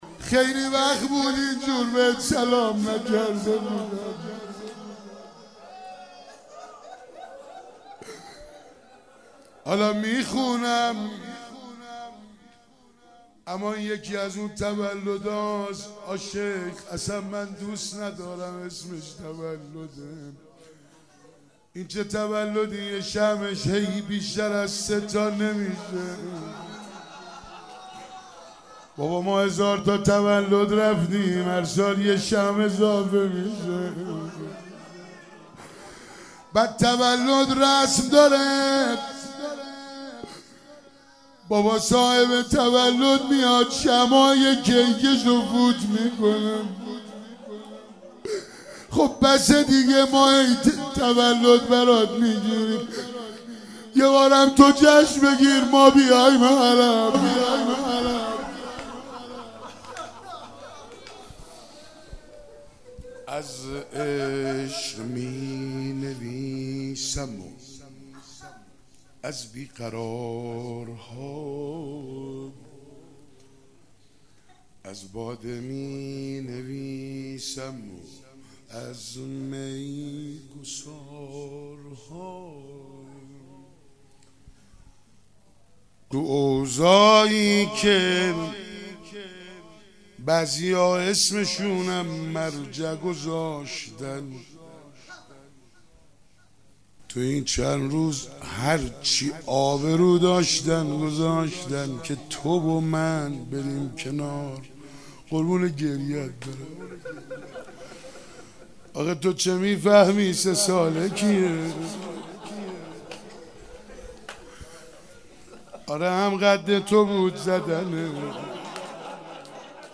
ولادت حضرت رقیه سلام الله علیها97 - شعرخوانی